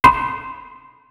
door_locked.wav